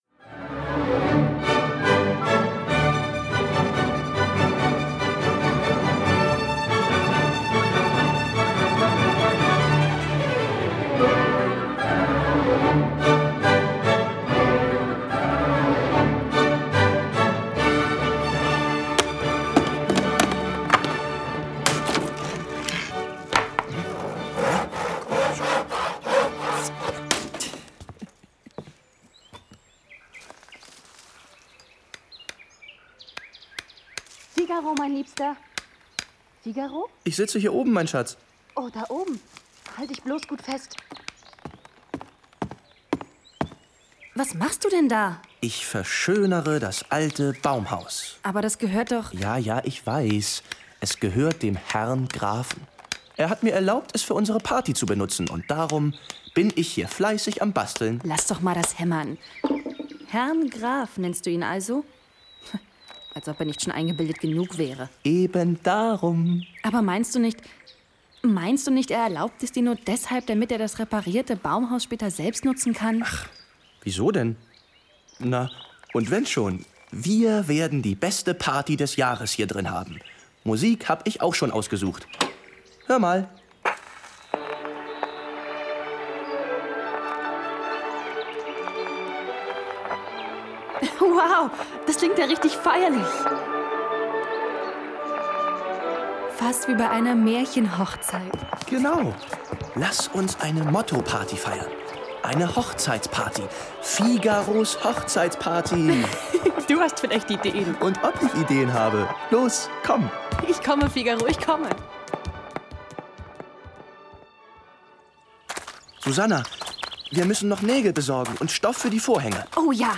Hörspiel mit Opernmusik